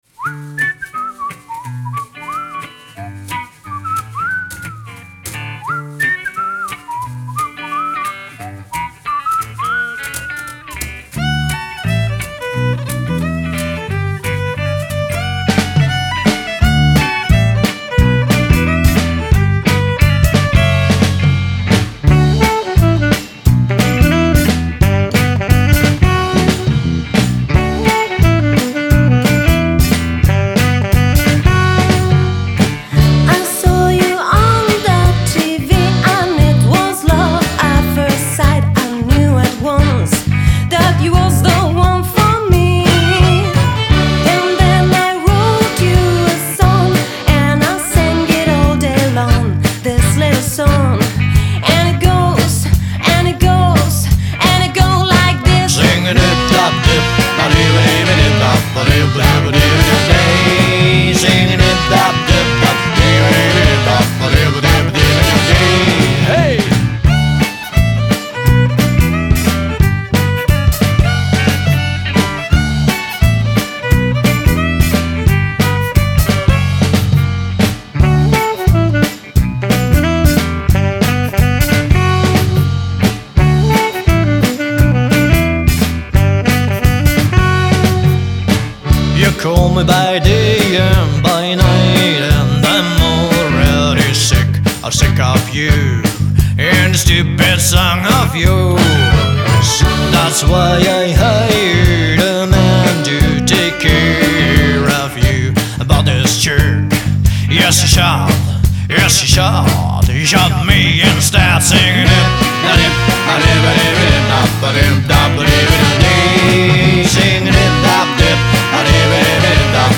Genre: Folk-Rock, Gypsy-Punk